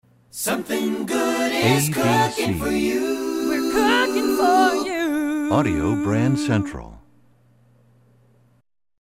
MCM Category: Ad Jingles